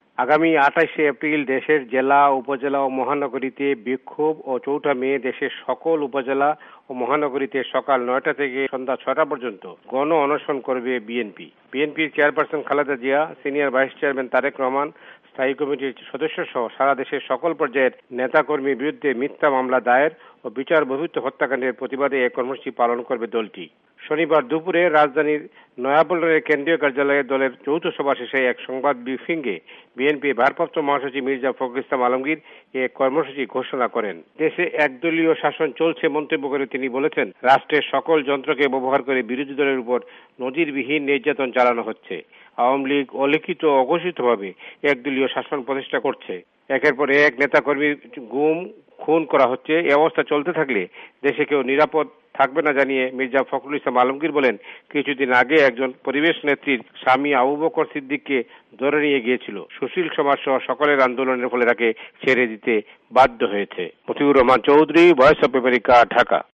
ভয়েস অব আমেরিকার ঢাকা সংবাদদাতাদের রিপোর্ট